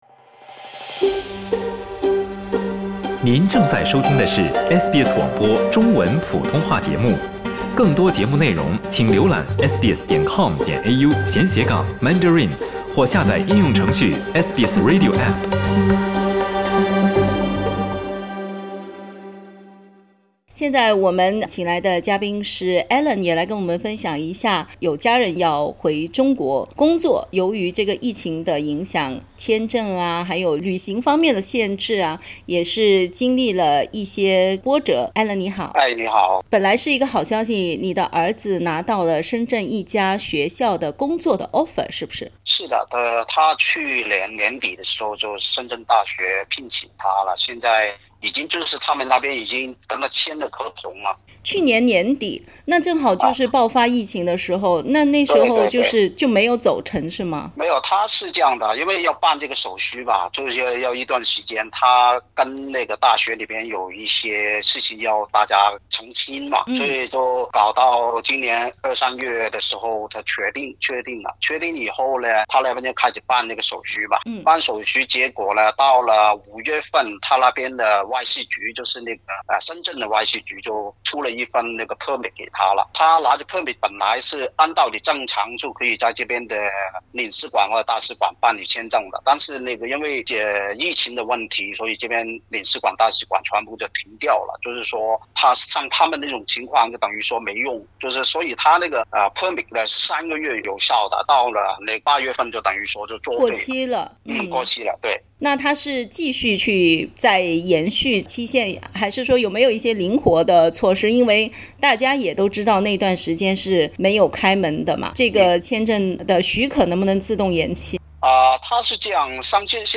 一位华裔澳大利亚公民去年获得博士学位后，获得中国一个大学的工作，但如今他也许只能望洋兴叹。 （点击图片收听详细录音采访）